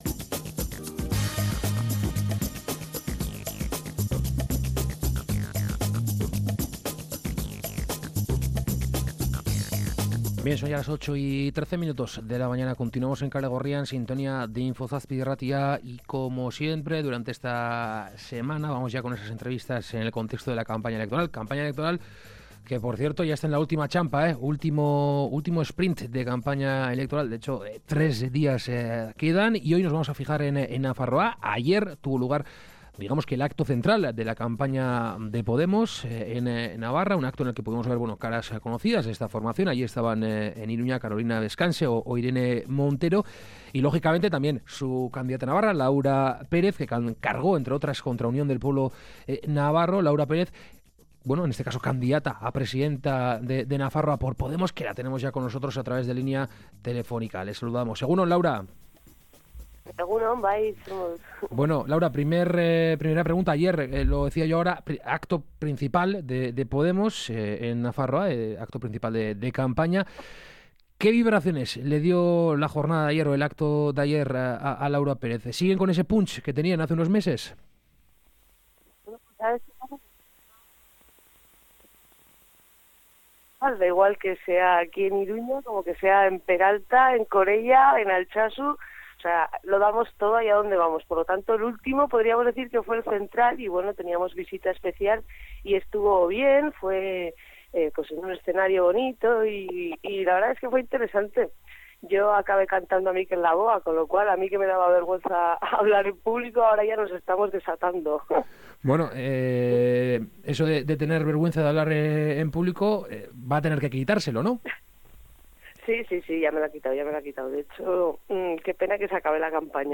Hoy, en el marco de las entrevistas que venismo realizando desde que comenzó la campaña electoral, hemos hablado con Laura Pérez, candidata de Podemos a la Presidencia de Nafarroa. Le hemos preguntado sobre los pacts post—ellectorales, las lineas generales de su propuesta electoral y sobre su postura con respecto al euskera, entre otras.